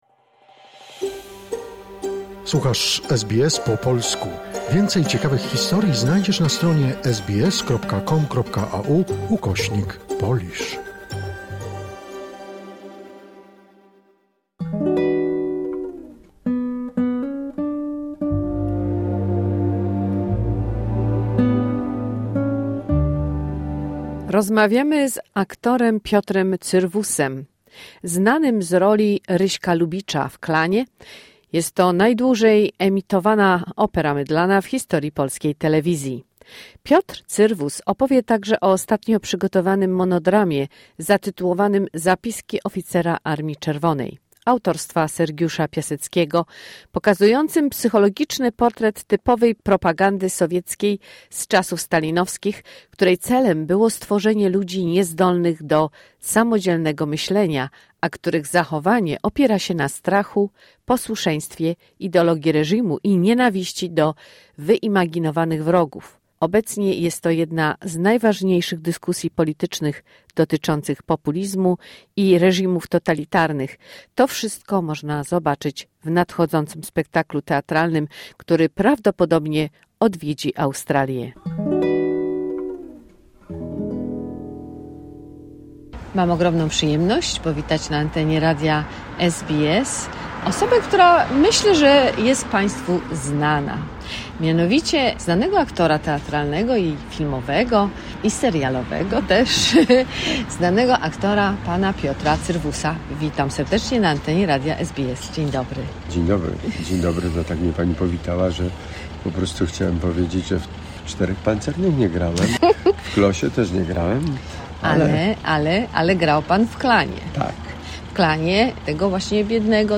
Rozmawiamy z aktorem teatralnym i filmowym Piotrem Cyrwusem, najbardziej znanym z roli Ryśka Lubicza w „Klanie”, najdłużej emitowanej operze mydlanej w historii polskiej telewizji.